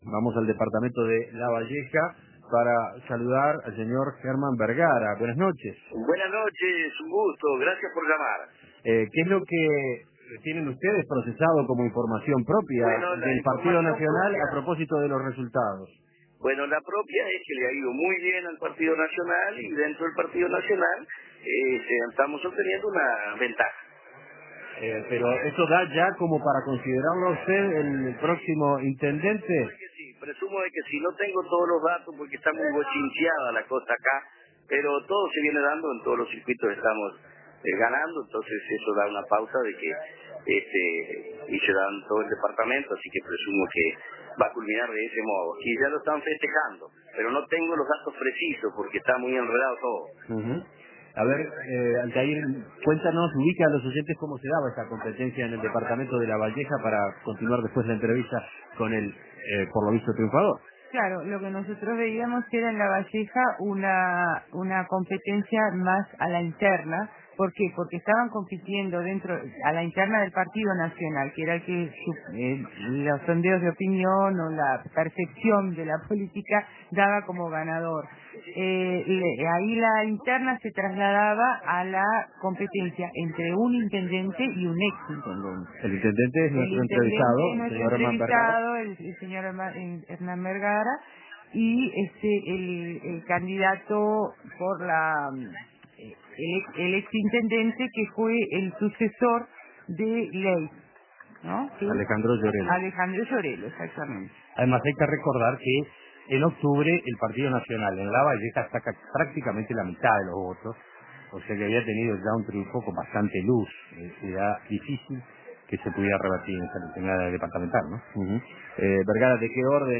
Entrevistas Lavalleja: Vergara mantuvo la municipalidad para el Partido Nacional Imprimir A- A A+ En Lavalleja, Herman Vergara, del Partido Nacional, es el nuevo intendente del departamento.